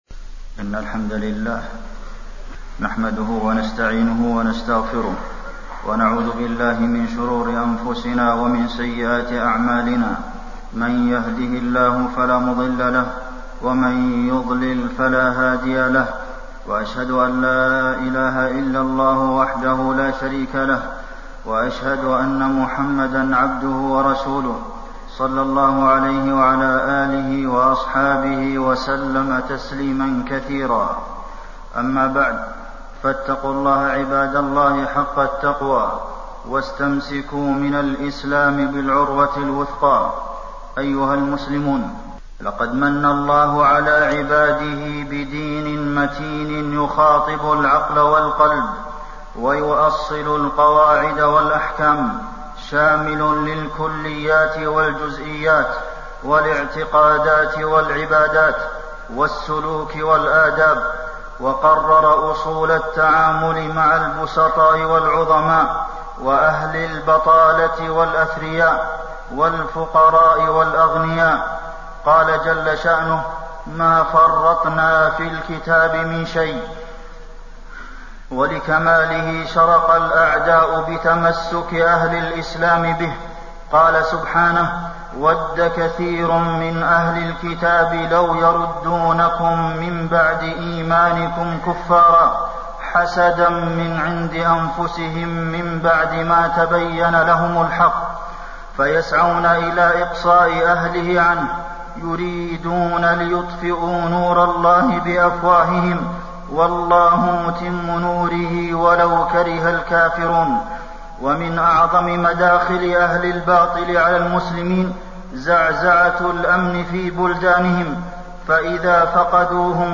تاريخ النشر ٢٠ ربيع الثاني ١٤٣٢ هـ المكان: المسجد النبوي الشيخ: فضيلة الشيخ د. عبدالمحسن بن محمد القاسم فضيلة الشيخ د. عبدالمحسن بن محمد القاسم خطورة الفتن إقبالا وإدبارا